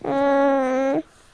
grunts.ogg